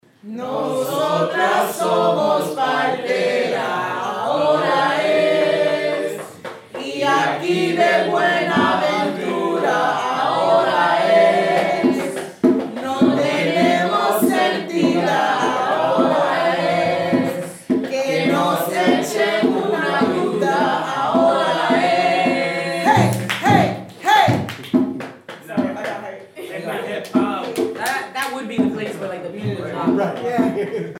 Each week, members of the choral study group assemble to learn a thematically related archival piece of vocal music by ear.
Norris Square Rehearsal